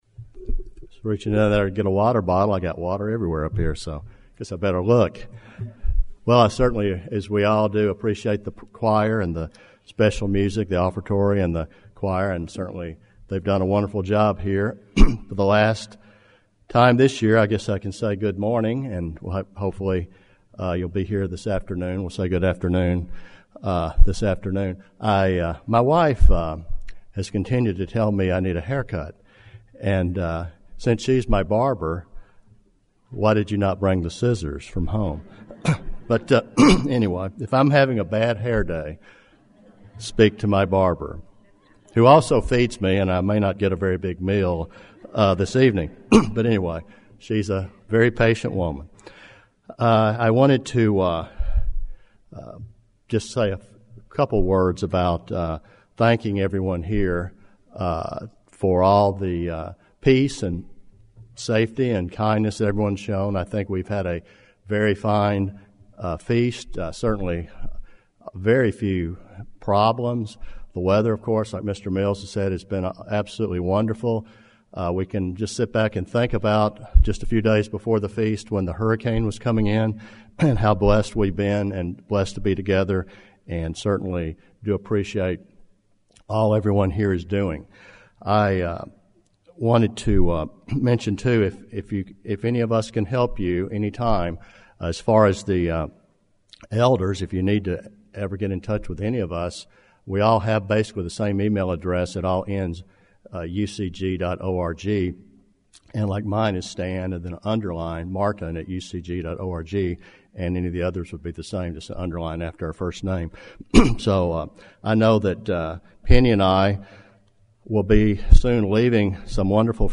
This sermon was given at the Jekyll Island, Georgia 2016 Feast site.